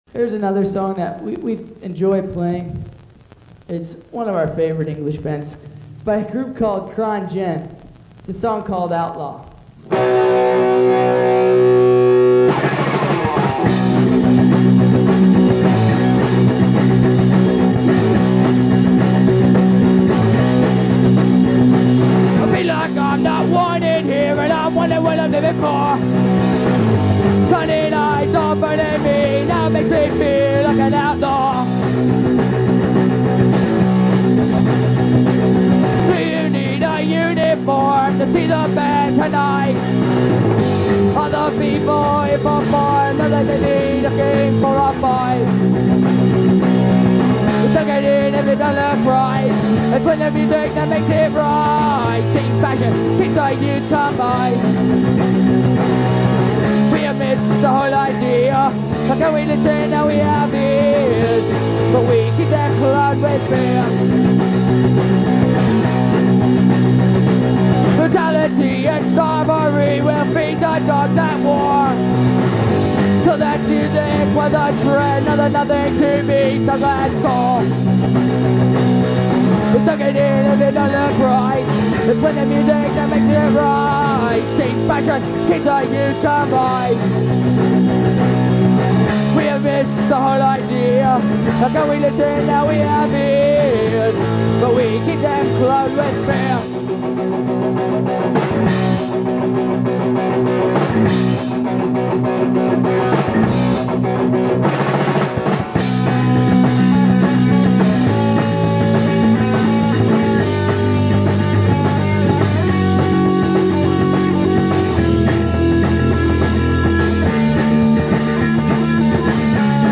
punk rock See all items with this value